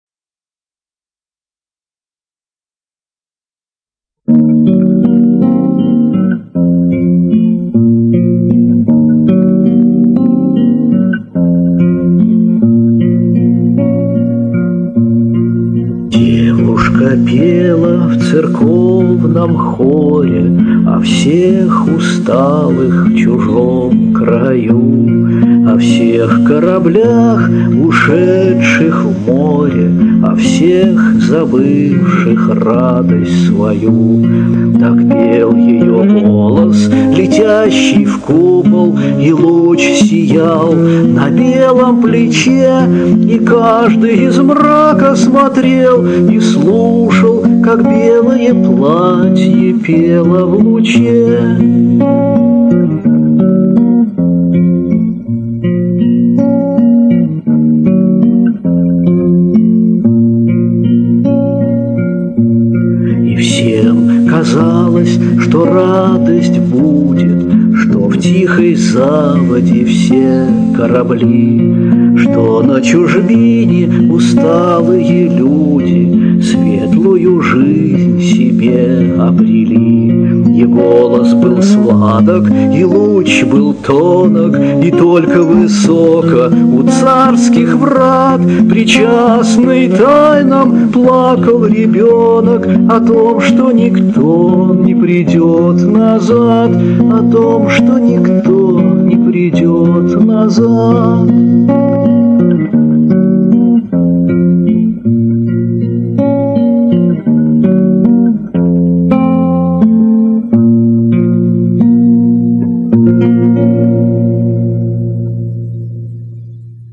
петербургский композитор, певец, декламатор, поэт.